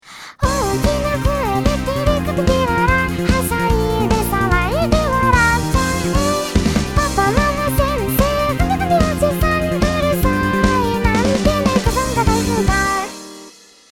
ピアノの低音が重いのかもと思ってベースとドラムを足してみた